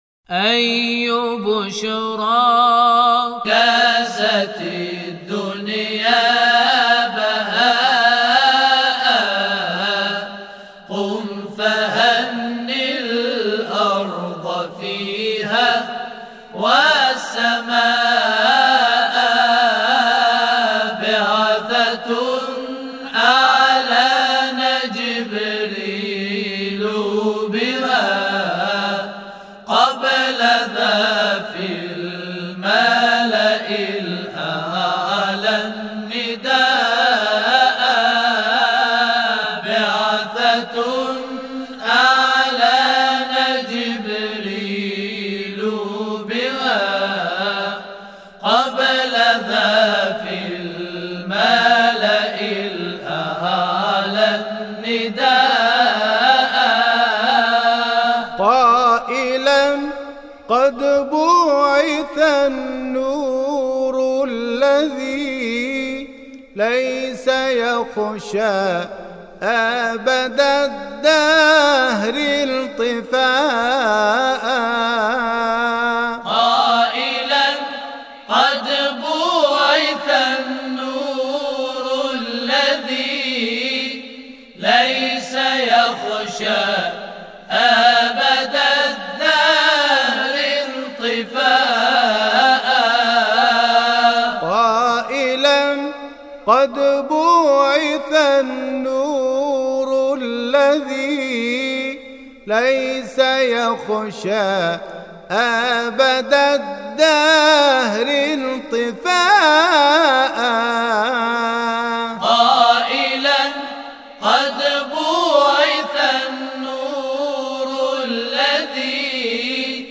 أي بشرى - مبعث النبی (ص) - لحفظ الملف في مجلد خاص اضغط بالزر الأيمن هنا ثم اختر (حفظ الهدف باسم - Save Target As) واختر المكان المناسب
tawashih_1435-2.mp3